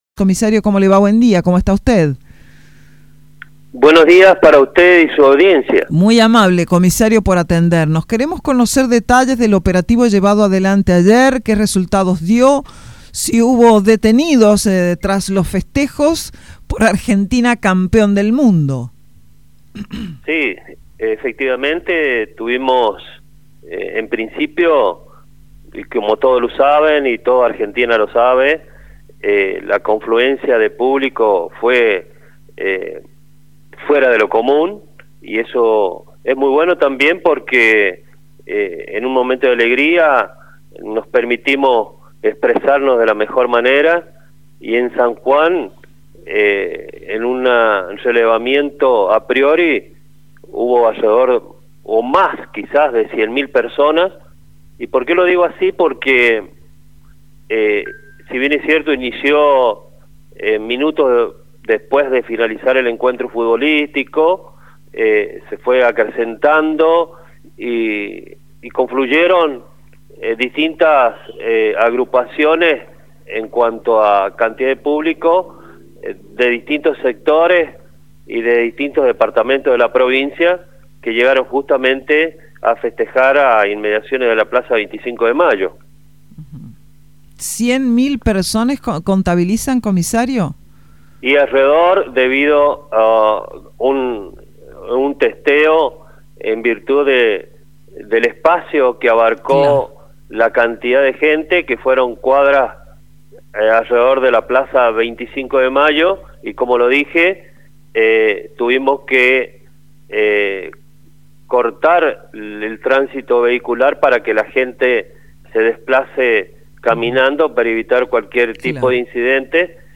En este marco, el Jefe de la Policía, comisario Gral. Luis Martínez, dialogó con Radio Sarmiento para brindar detalles.